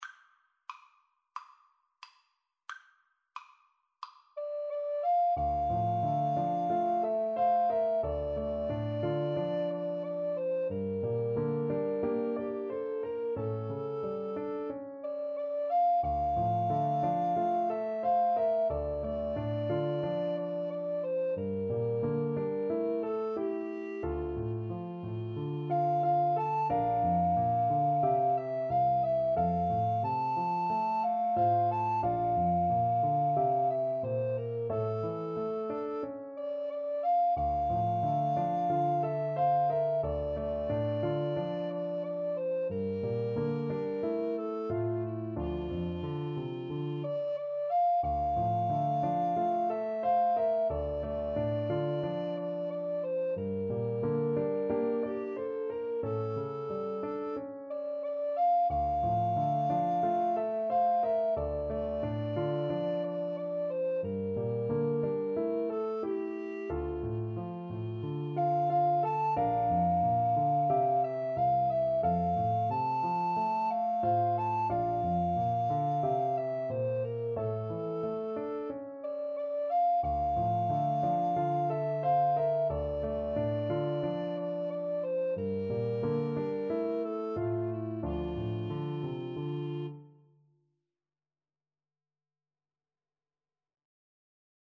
D minor (Sounding Pitch) (View more D minor Music for Recorder Duet )
Andante = c. 90